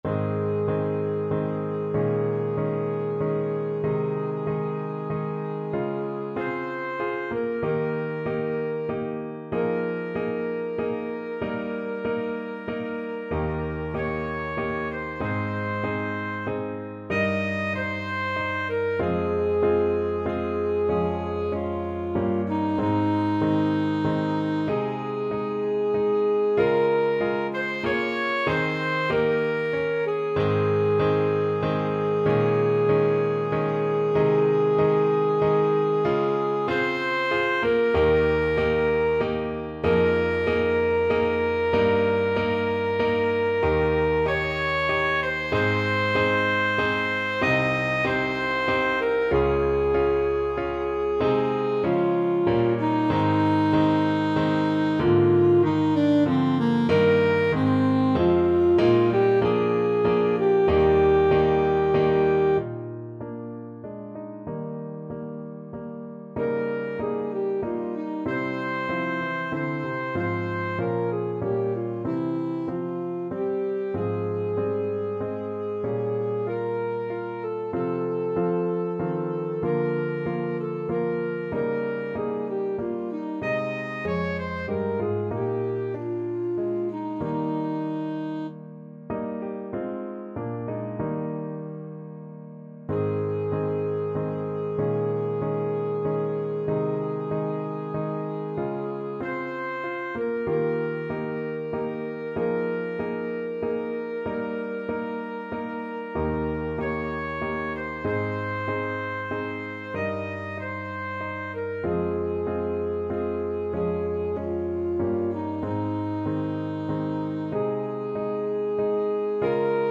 Classical Handel, George Frideric Thanks Be to Thee (Dank sei dir Herr) Alto Saxophone version
Alto Saxophone
Ab major (Sounding Pitch) F major (Alto Saxophone in Eb) (View more Ab major Music for Saxophone )
=95 Andante
3/4 (View more 3/4 Music)
Bb4-Eb6
Classical (View more Classical Saxophone Music)